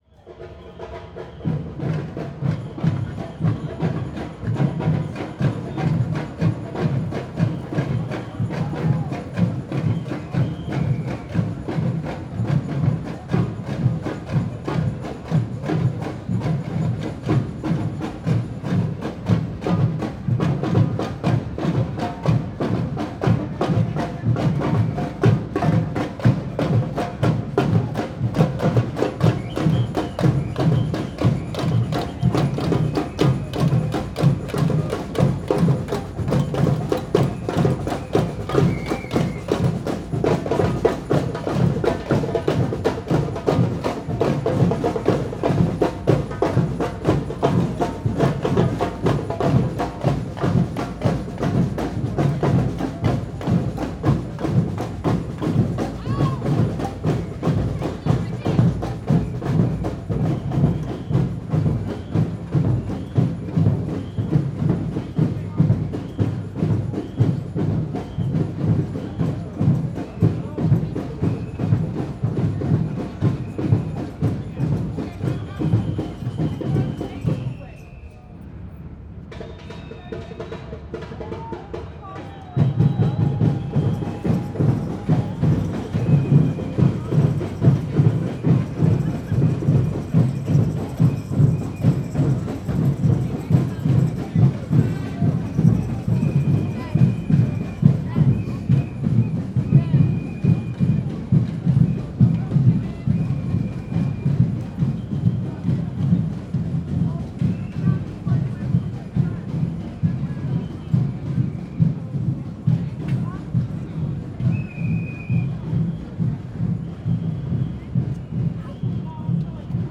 streetparty1.L.wav